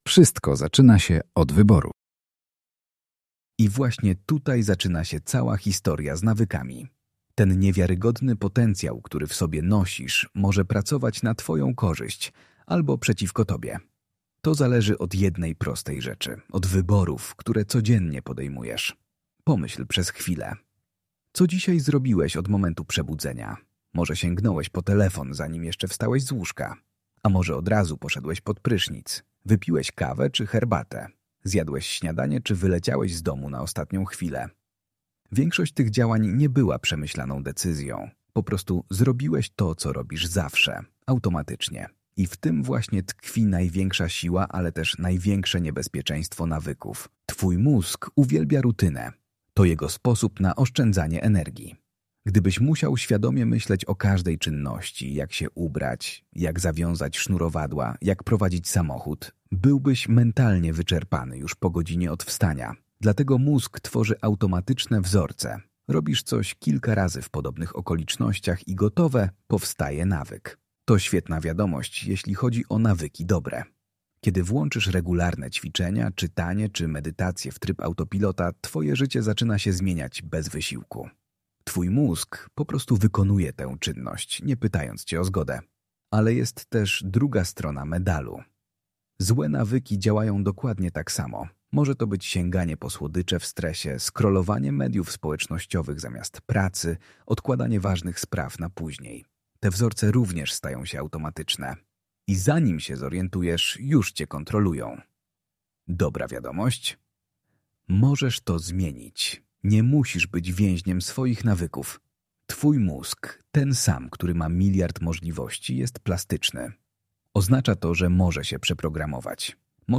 Pobierz PDF Pobierz MP3 Posłuchaj 🎧 Nawyki bez wysiłku — Audiobook Twoja przeglądarka nie obsługuje odtwarzacza audio.